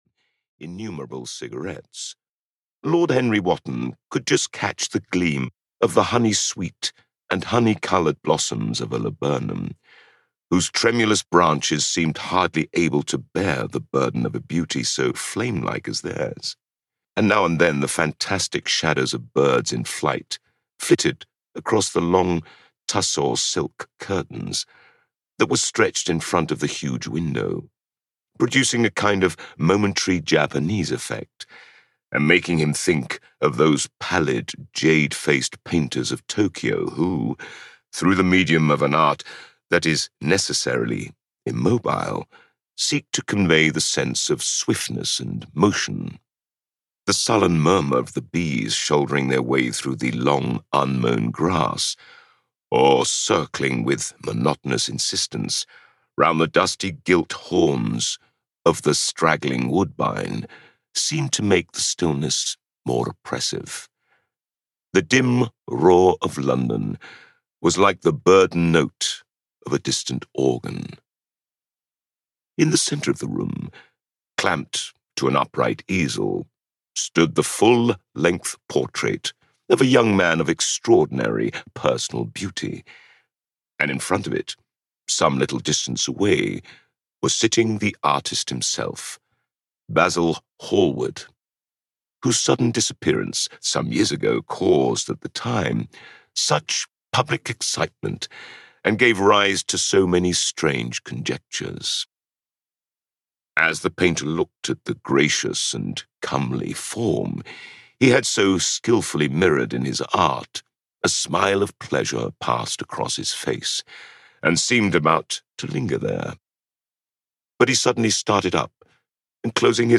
The Picture of Dorian Gray (EN) audiokniha
Ukázka z knihy